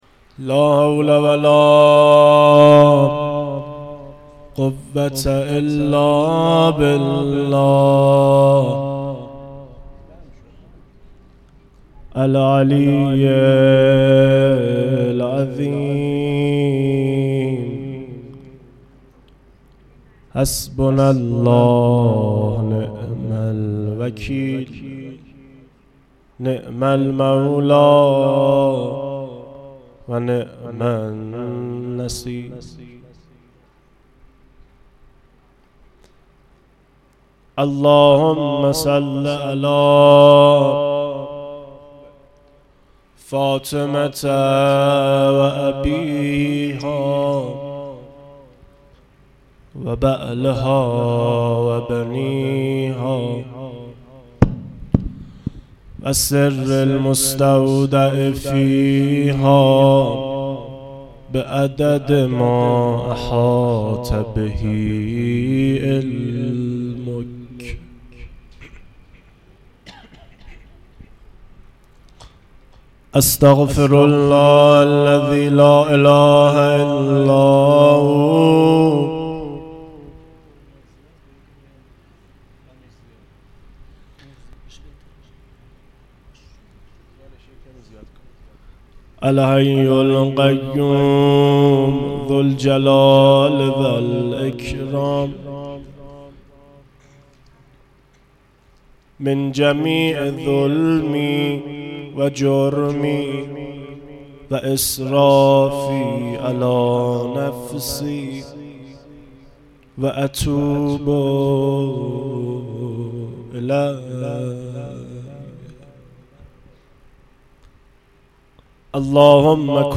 روضه
روضه-11.mp3